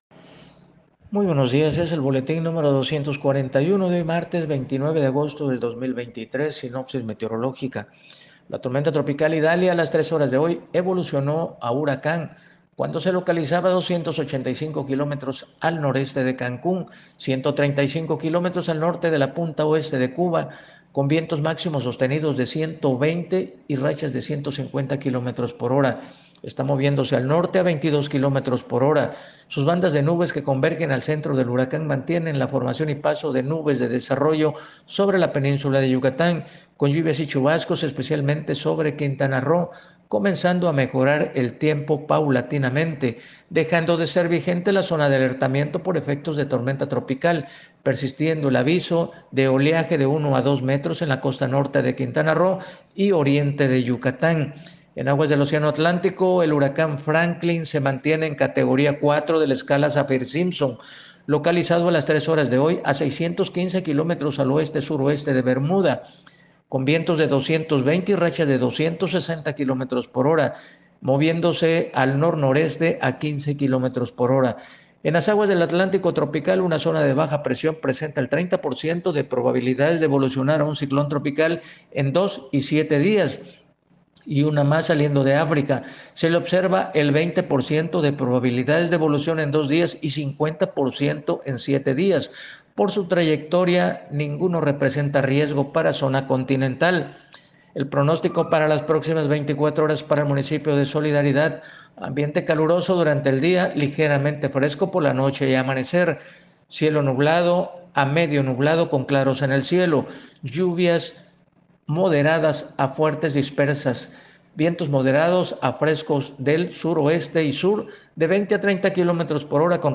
Reporte meteorológico 29 agosto 2023